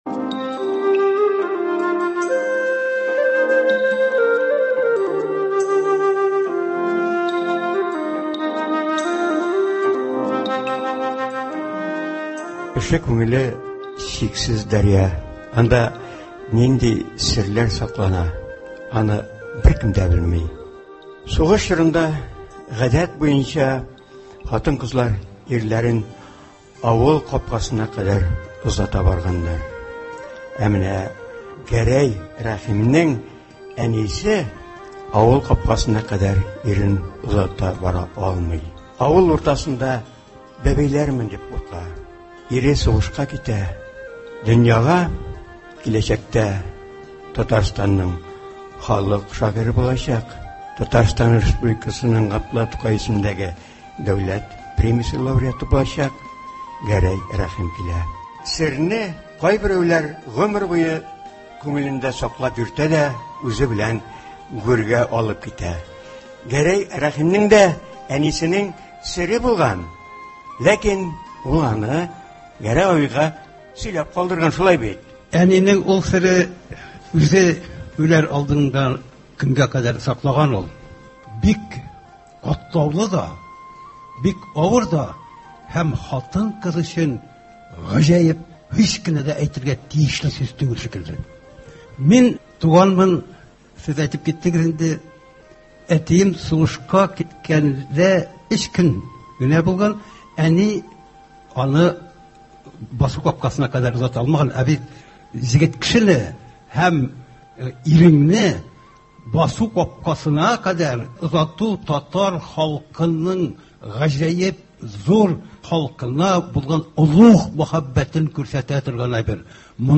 Татарстан дәүләт телерадиокомпаниясе Бөтендөнья татар конгрессы, Бөтенроссия татар гаиләсе фонды белән берлектә Бөек Ватан сугышы башлануга 80 ел тулган көнне сәхнәдән “Пар канатлар” тапшыруының махсус чыгарылышын үткәргән иде. Тапшыруда бу кичәнең 2 нче өлеше кабатлап бирелә.